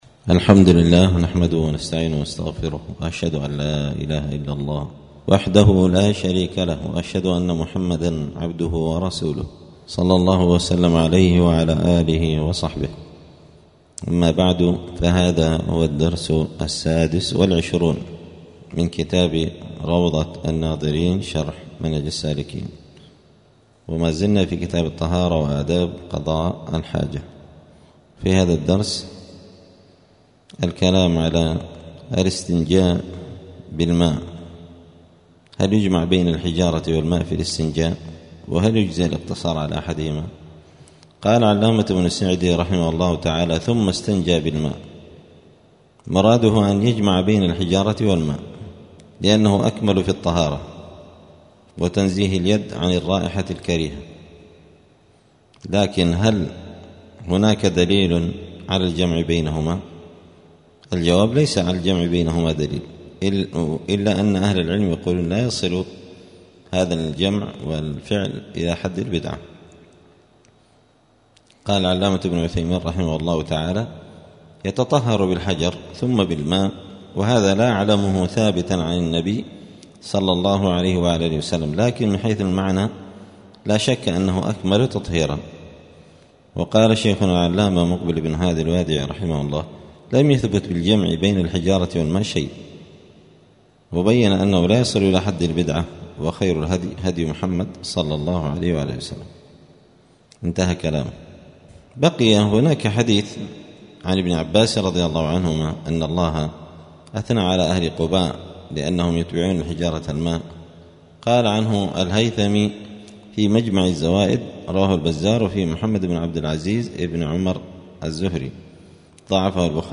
الجمعة 29 ربيع الثاني 1446 هــــ | الدروس، دروس الفقة و اصوله، كتاب روضة الناظرين شرح منهج السالكين | شارك بتعليقك | 35 المشاهدات